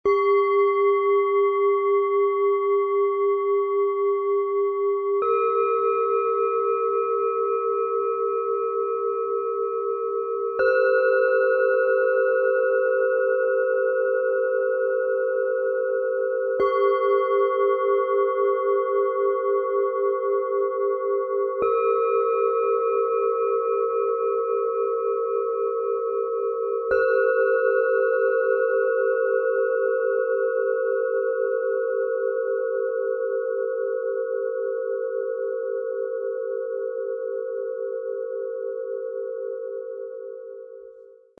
Ankommen im Jetzt, Ruhe, Herzöffnung, Heiterkeit - Set aus 3 Klangschalen, Ø 12,3 - 13,3 cm, 1,46 kg
Ihr tiefer, tragender Ton schenkt ein gutes Gefühl im Bauch und holt dich bei dir selbst ab.
Diese Schale klingt warm und offen.
Der helle Ton dieser Schale hebt den Blick nach innen wie außen.
Im Sound-Player - Jetzt reinhören können Sie den Original-Ton genau dieser Schalen anhören – sanft, zentrierend und heiter.
Bengalen-Schale, Schwarz-Gold